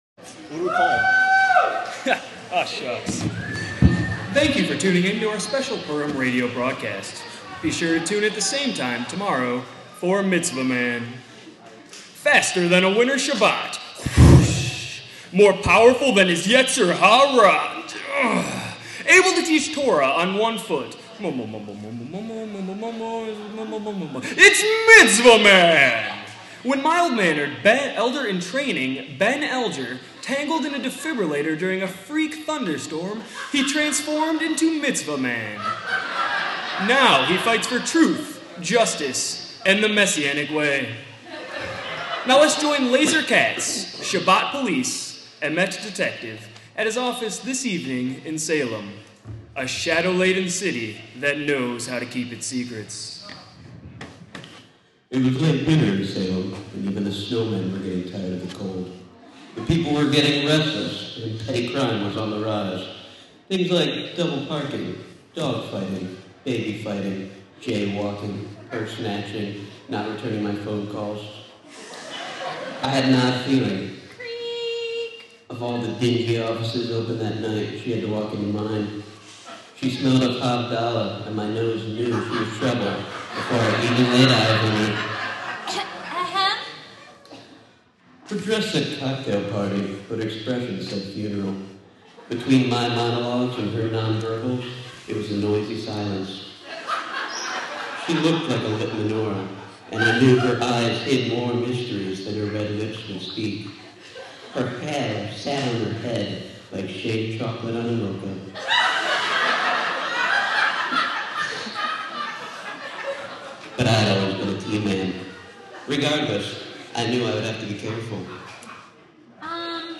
Join Beth Tikkun as we explore the mo'ed of Purim in this second part of a two-part Purim teaching.